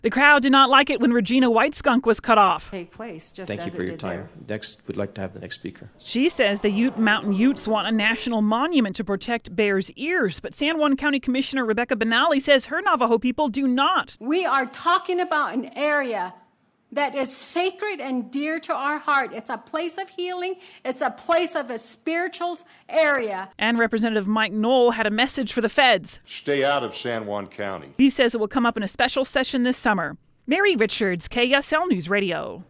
Emotional back and forth over whether Bears Ears ought to be made into a national monument. The testimony came during a meeting of the Commission for the Stewardship of Public Lands today.